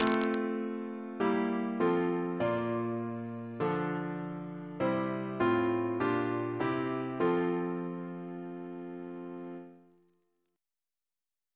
Single chant in F Composer: Leopold Lancaster Dix (1861-1935) Reference psalters: ACB: 364